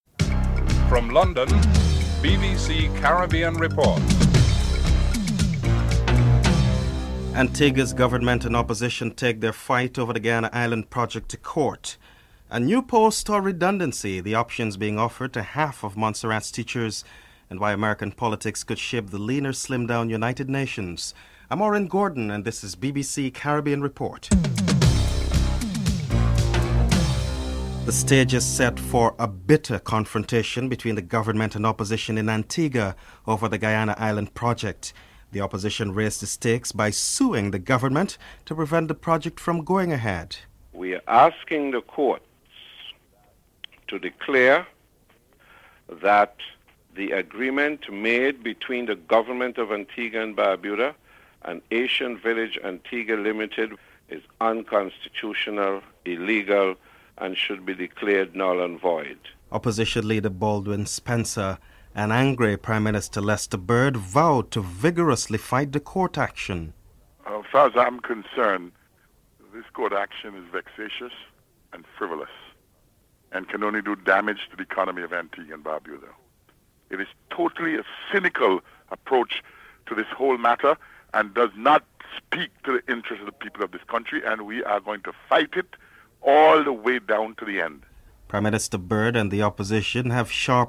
1. Headlines (00:00-00:27)
2. Antigua's government and opposition take their fight over the Guiana Island project to court. Opposition Leader, Baldwin Spencer and Prime Minister Lester Bird are interviewed (00:28-04:44)